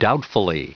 Prononciation du mot doubtfully en anglais (fichier audio)
Prononciation du mot : doubtfully
doubtfully.wav